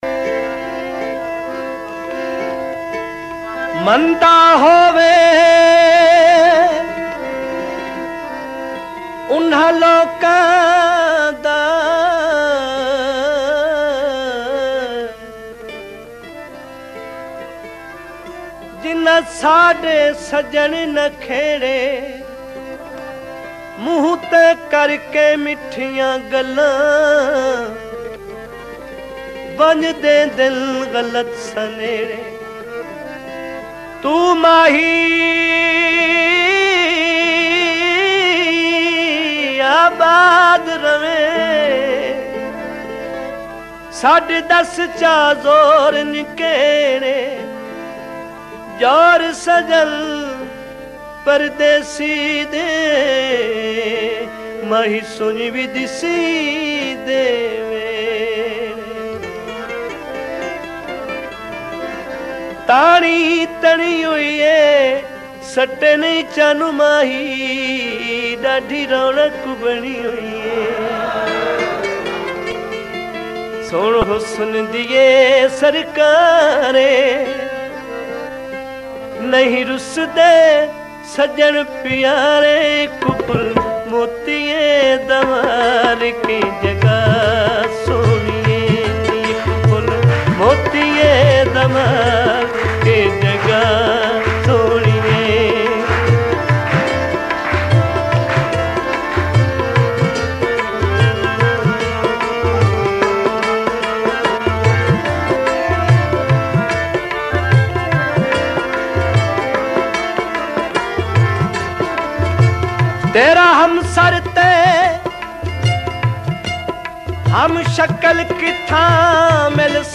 Punjabi Folk Kalam